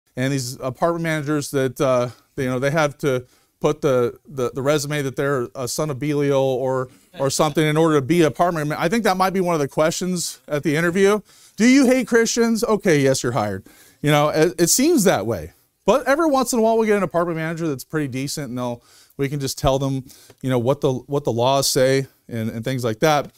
Sermon Clips